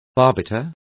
Complete with pronunciation of the translation of arbiter.